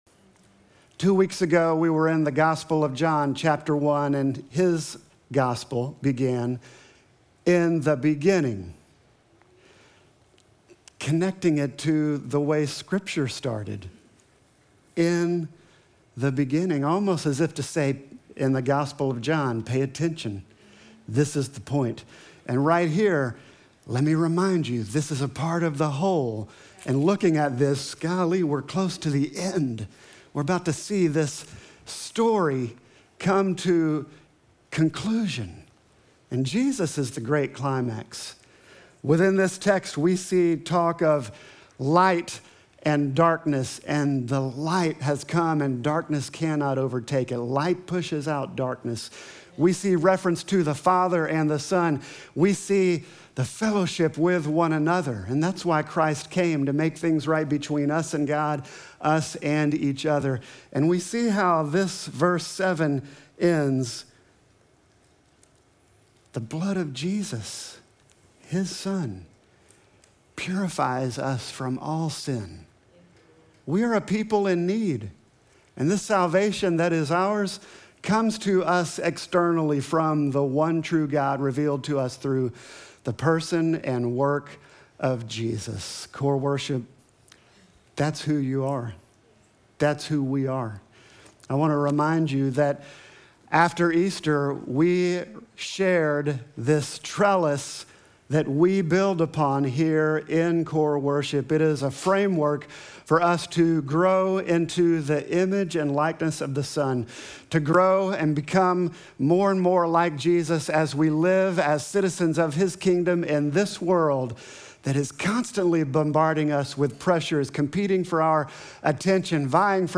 Sermon text: 1 John 1:1-7